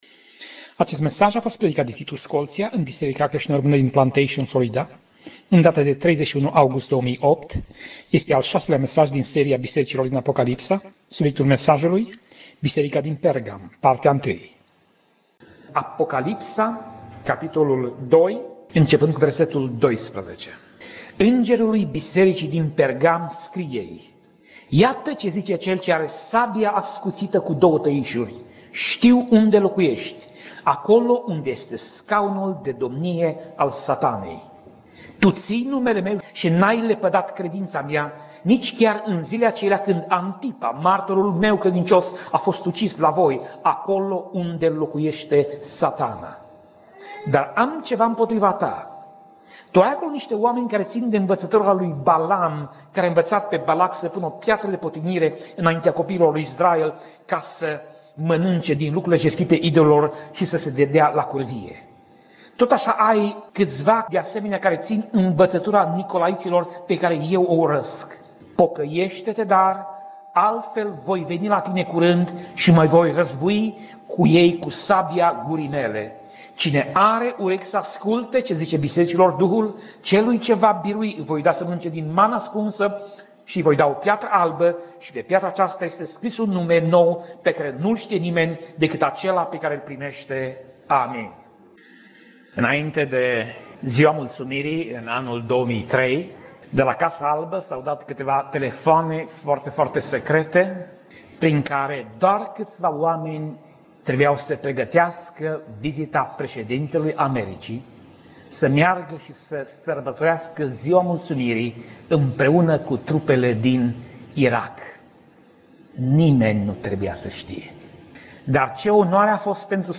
Pasaj Biblie: Apocalipsa 2:12 - Apocalipsa 2:17 Tip Mesaj: Predica